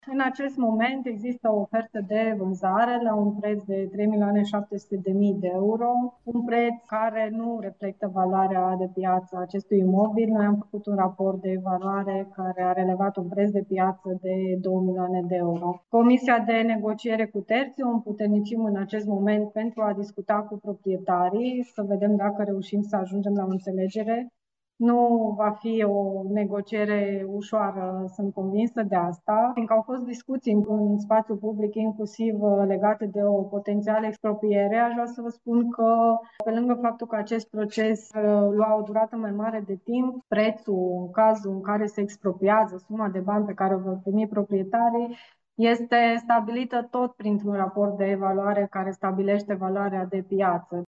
Viceprimarul Paula Romocean spune că se așteaptă ca negocierile să fie dure și a explicat de ce se apelează la negocieri și nu la expropriere.